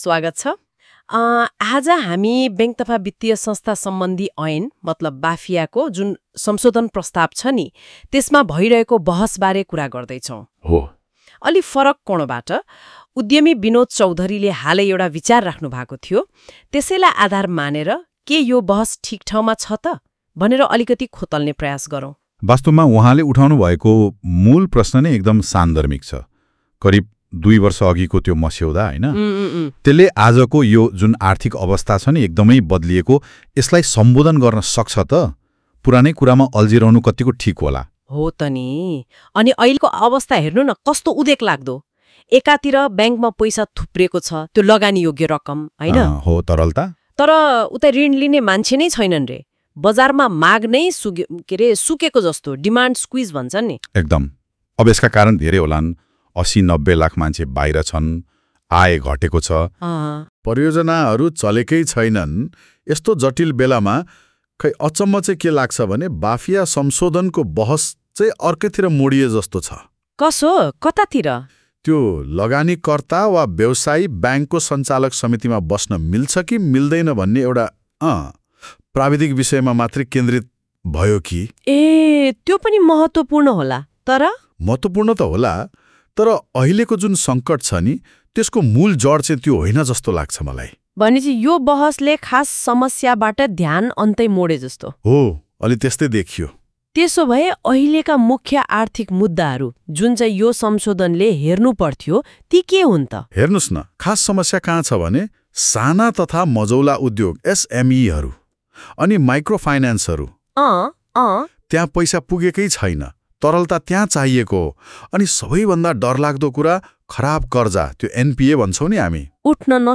उनै सांसद चौधरीले समितिमा राखेका धारणालाई हामीले एआई प्रयोग गरेर उक्त छलफल यहाँ प्रस्तुत गरिएका छौं ।